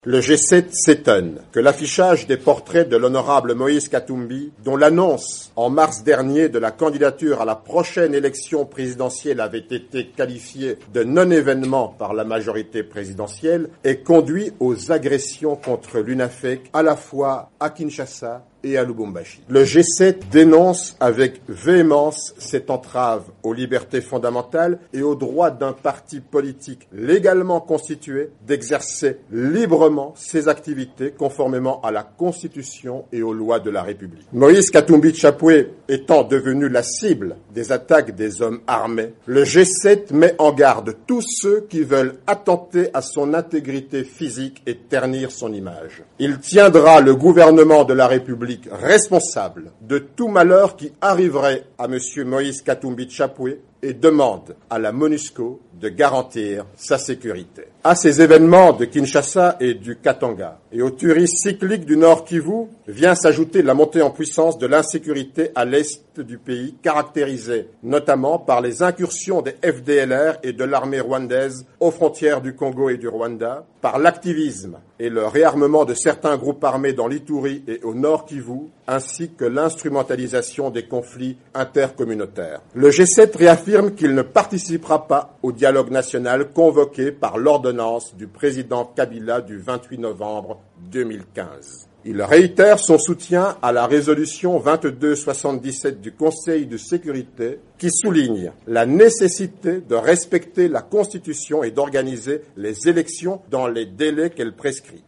Olivier Kamitatu au micro de Top Congo FM, notre radio partenaire à Kinshasa